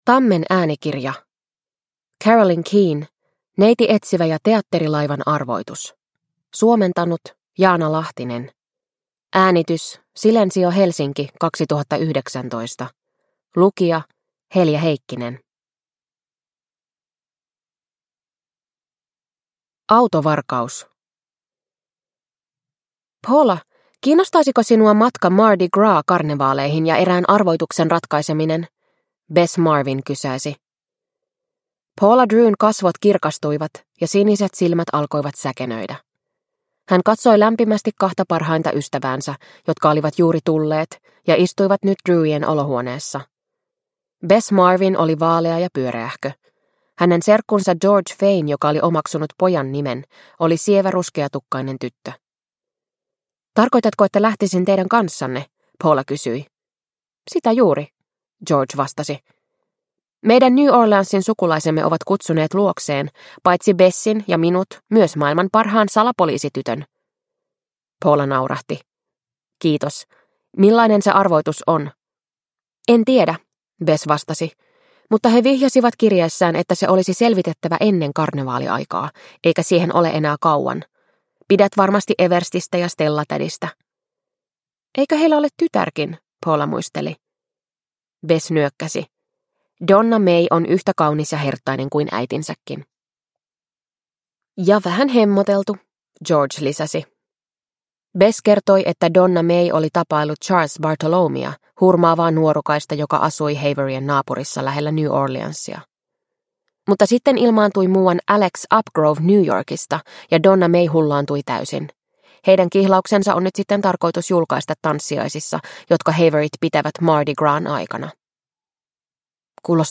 Neiti Etsivä ja teatterilaivan arvoitus – Ljudbok – Laddas ner